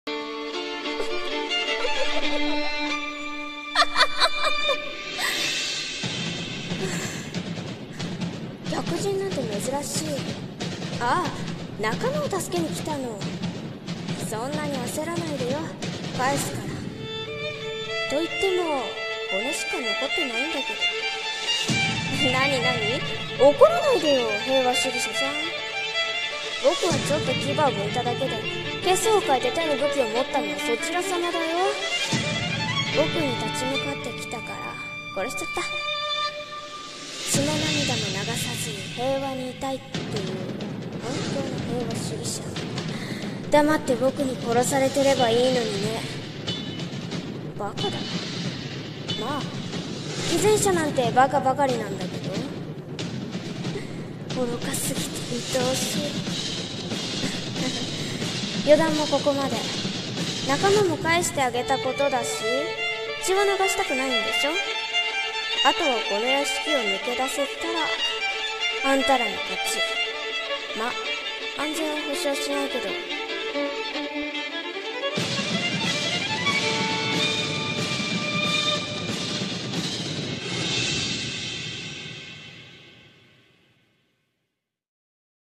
【1人声劇】偽善者